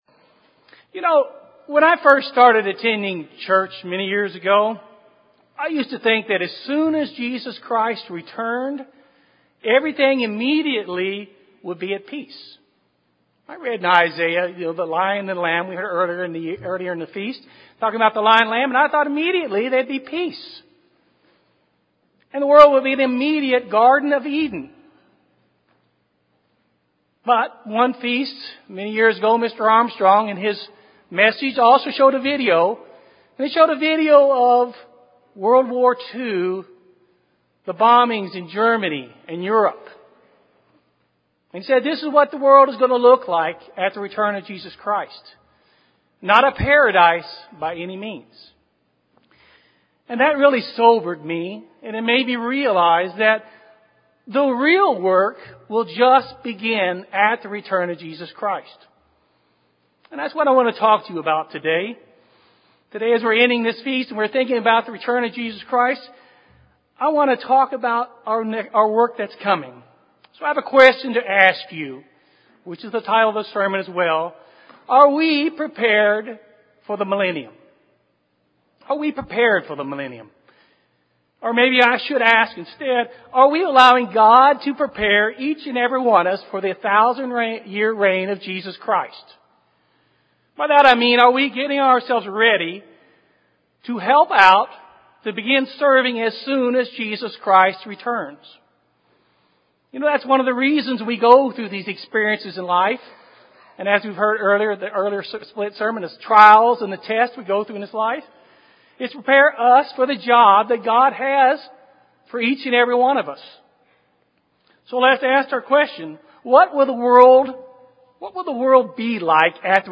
This sermon was given at the Galveston, Texas 2015 Feast site.